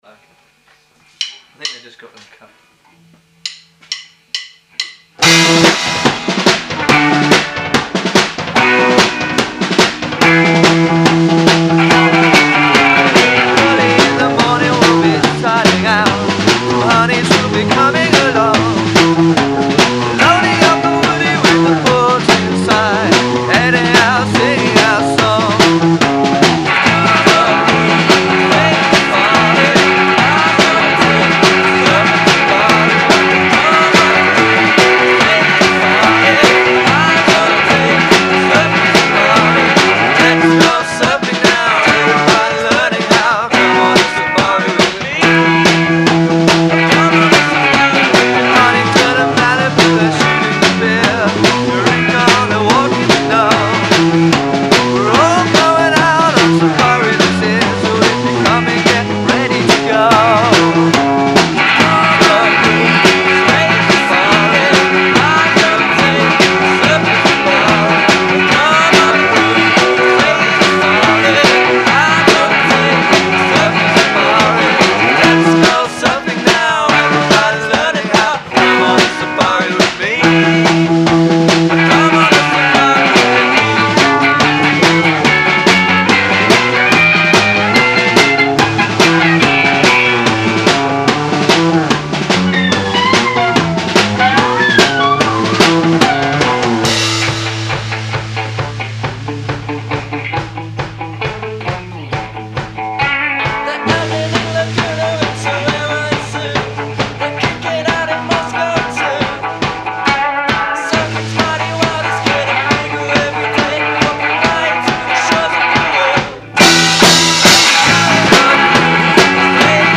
un curioso divertissement a bassa fedeltà